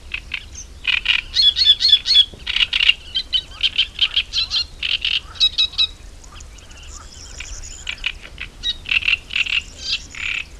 4. 1. 1. 노래
디아차 보트로나 습지(이탈리아)에서 녹음된 노래